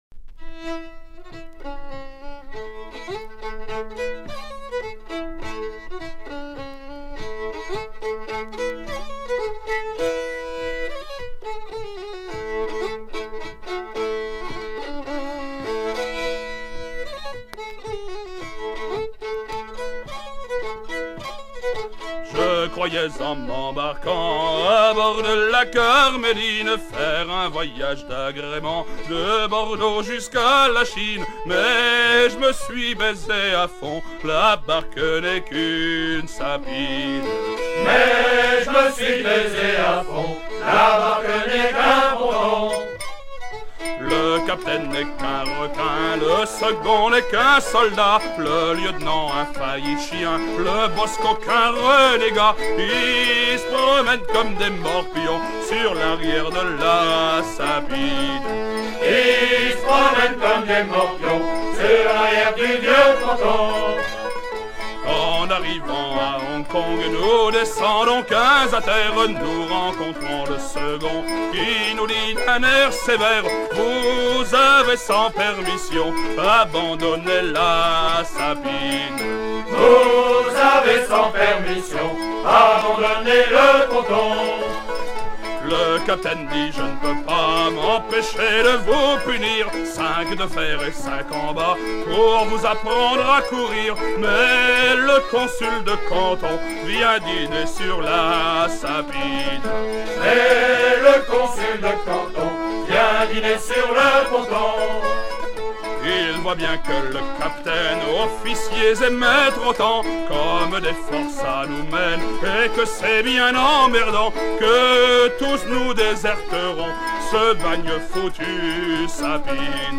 à virer au cabestan
Genre strophique
Pièce musicale éditée